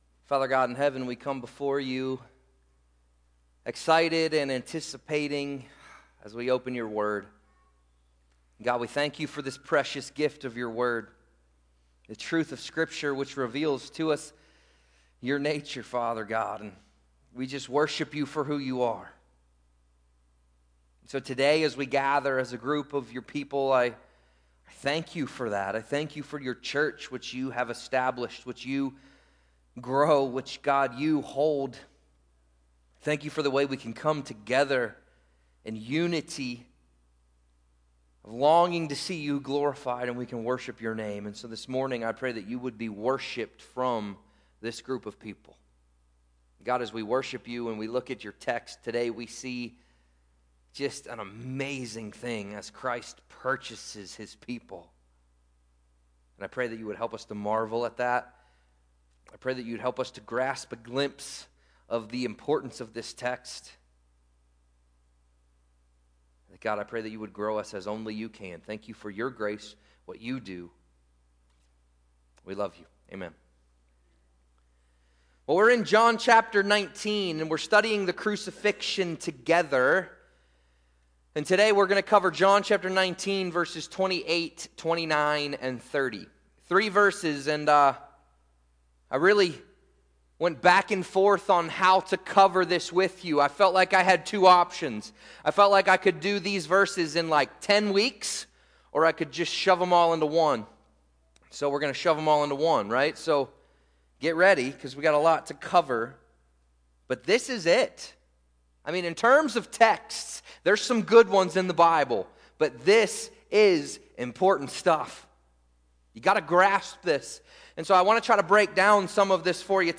Sermons – North Street Christian Church